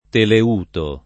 teleuto [ tele 2 to ]